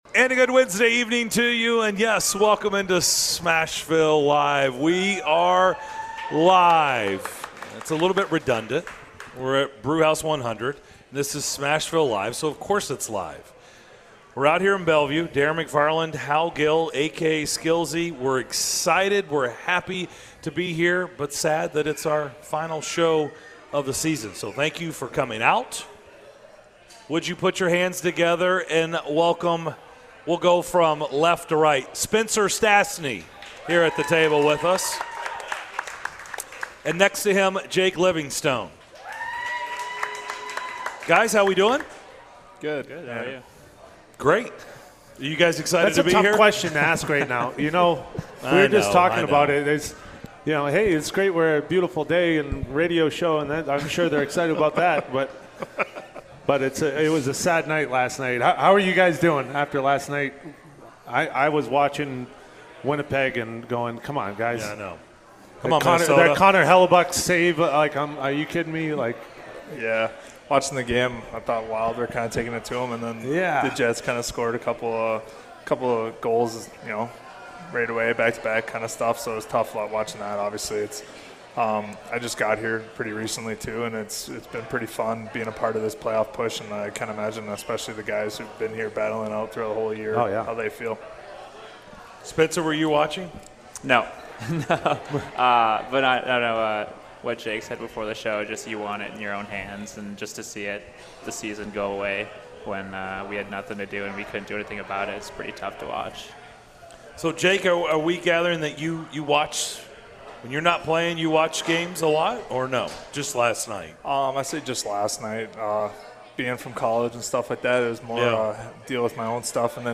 In the final edition of Smashville Live at Brewhouse 100